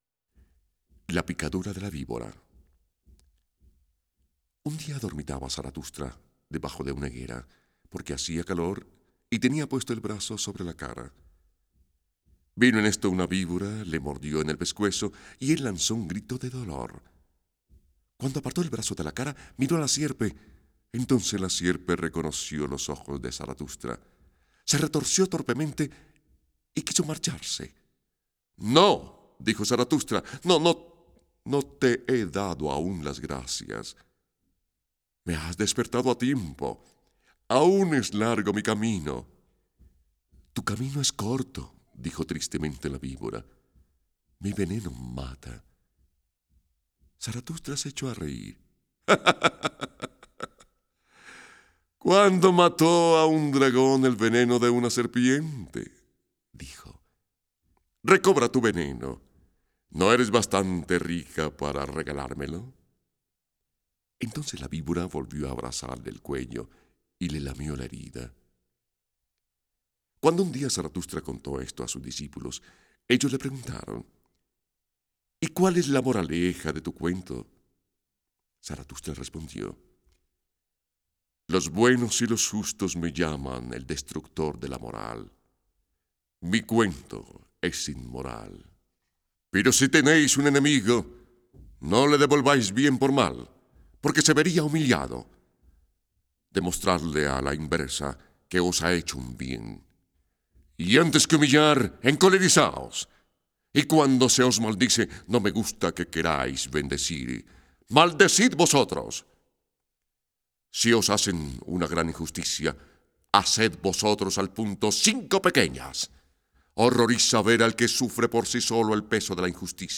Libros Gnosticos en Audio Mp3